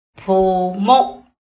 臺灣客語拼音學習網-進階學習課程-海陸腔-第五課
u / ug